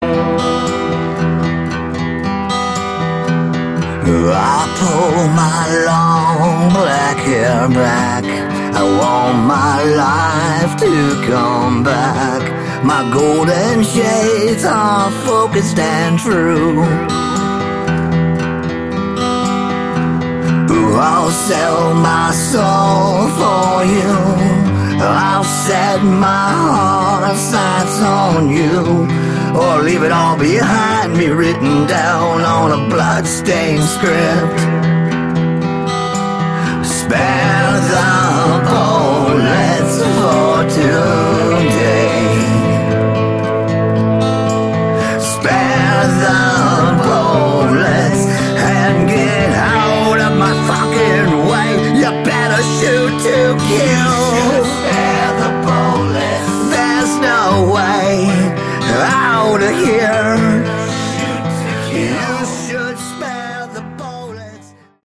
Category: Sleaze Glam/Punk
lead vocals, guitars, keyboards, piano
guitars, backing vocals